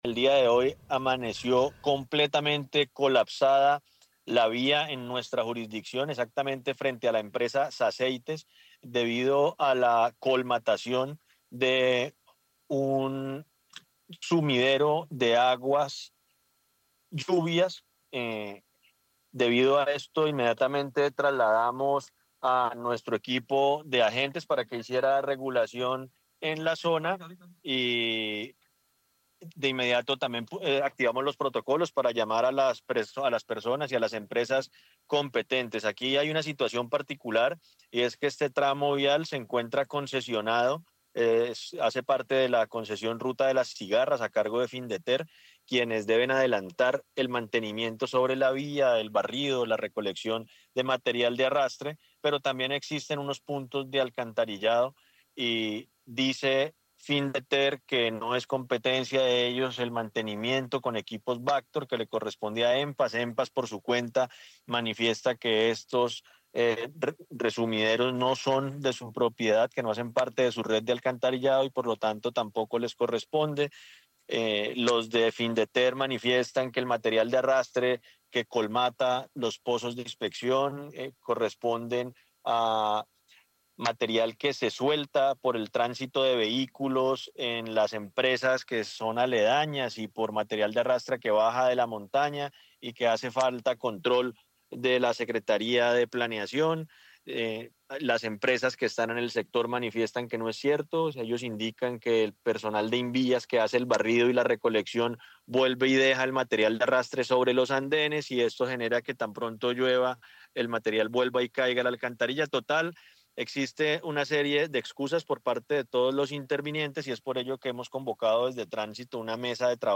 Alcaldía de Girón explica razones de congestión en el anillo vial
Más tarde, en entrevista con el noticiero del mediodía de Caracol Radio, el funcionario dio cuenta de las causas del trancón.